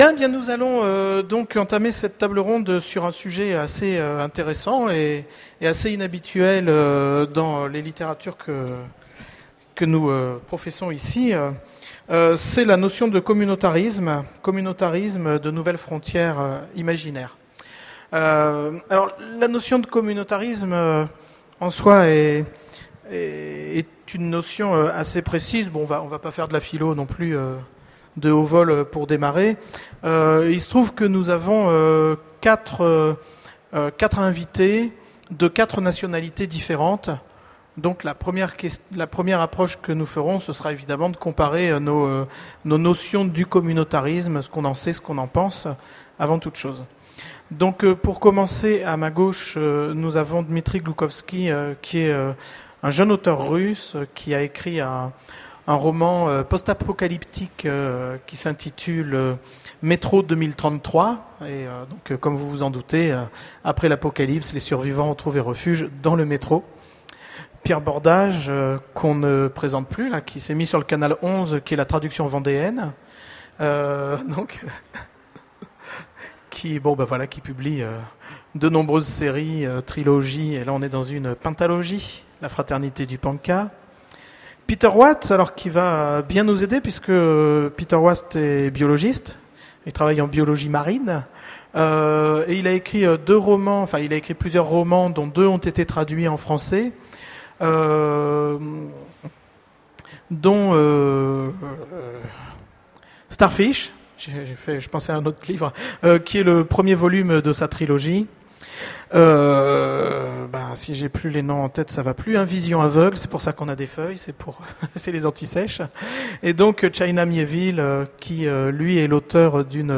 Utopiales 2010 :Conférence Le communautarisme, de nouvelles frontières imaginaires ?